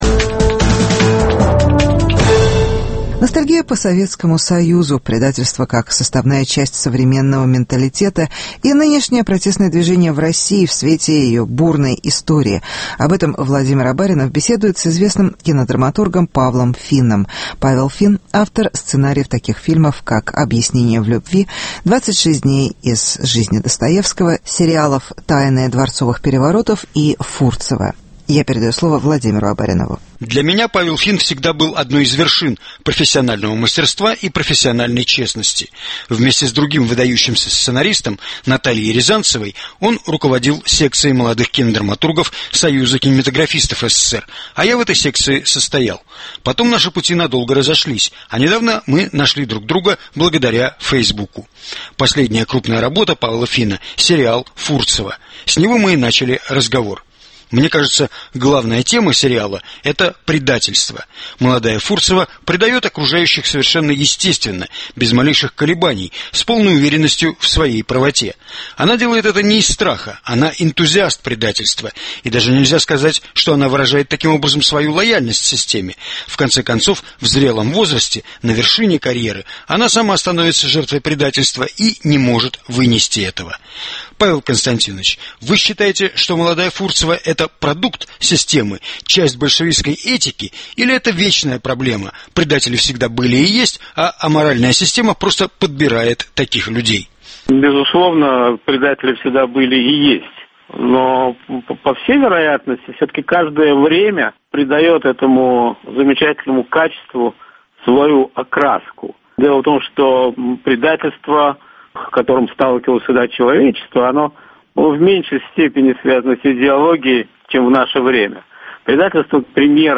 Вечная российская зубатовщина: интервью с кинодраматургом Павлом Финном